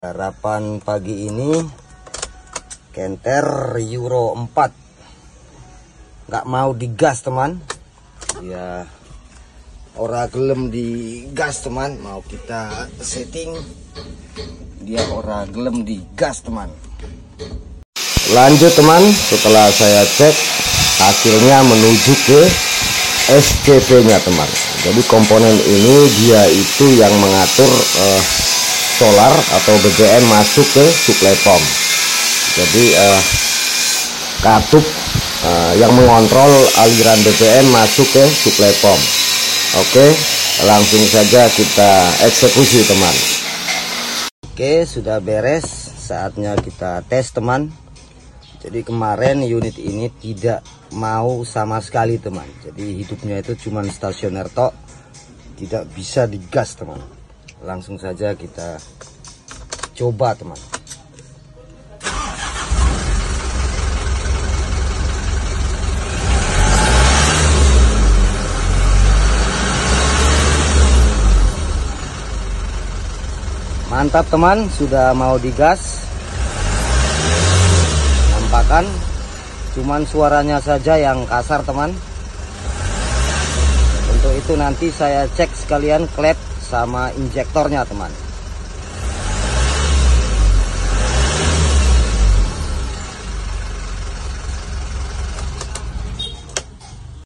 Trouble Shooting Engine Mitsubishi Canter